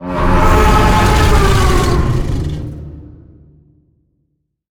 Sfx_creature_hiddencroc_roar_03.ogg